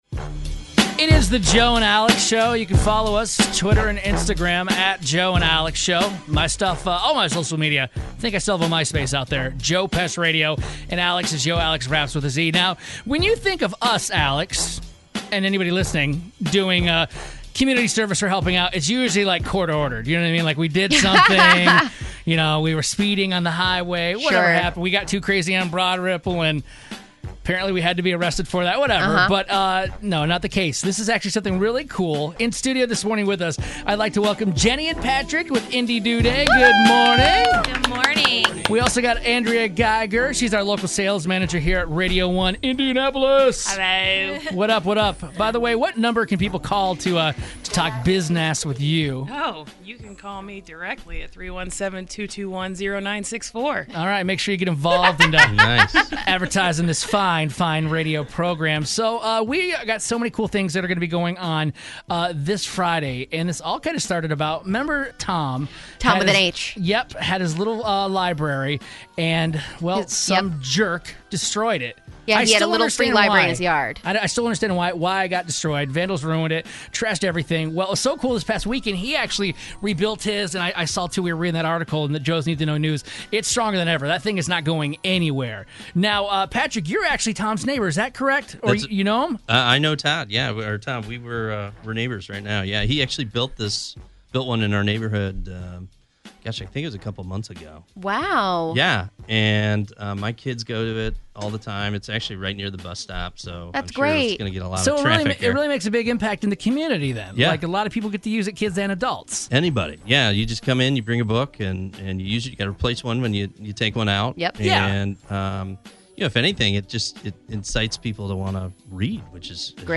The Little Free Library Interview